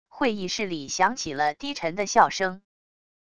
会议室里响起了低沉的笑声wav音频